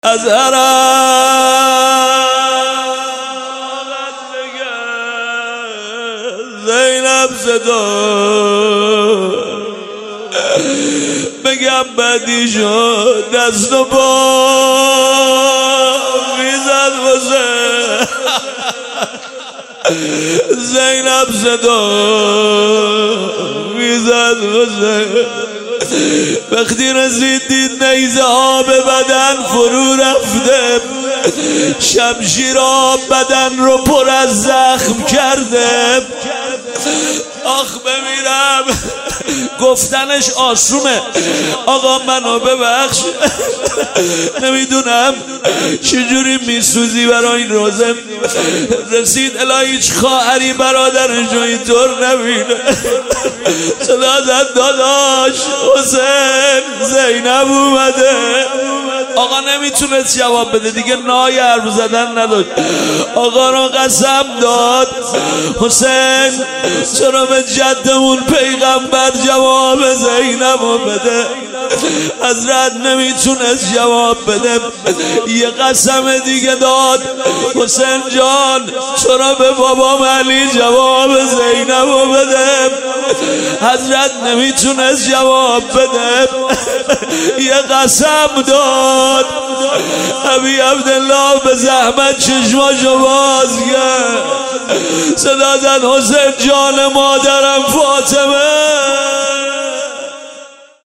شهادت حضرت زینب سلام الله علیها 1399 | هیئت خادمین حضرت زینب سلام الله علیها قم